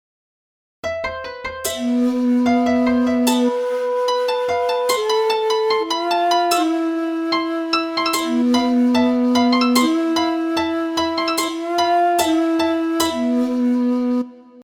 日本音階
聞いてすぐ「和風だな」と思う曲、ありますよね。
こちらが今回のサンプルに使ったスケールで、
大きくは「日本音階」と呼ばれます。